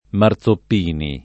Marzoppini [ mar Z opp & ni ] cogn.